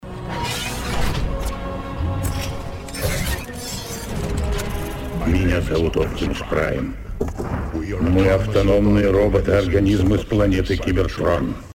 • Качество: 174, Stereo